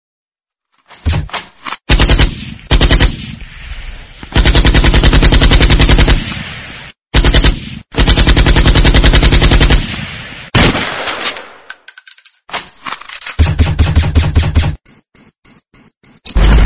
Kategori Lydeffekt